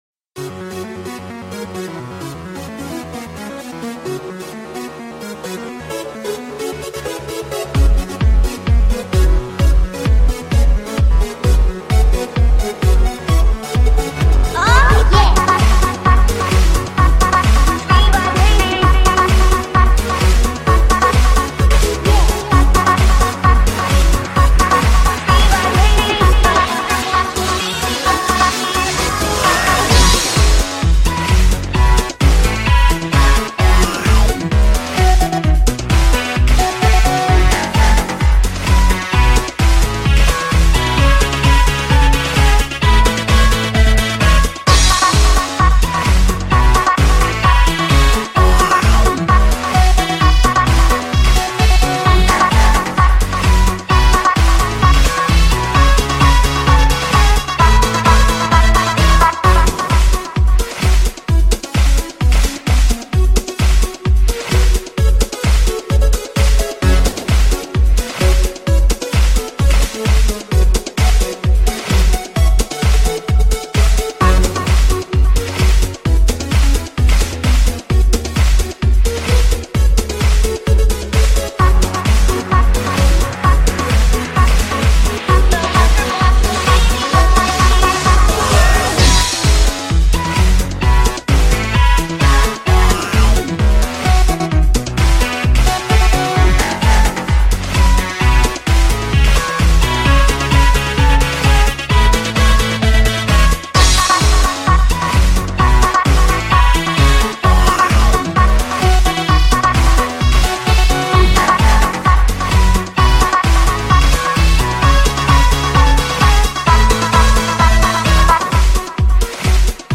My try at Vocal Chopping.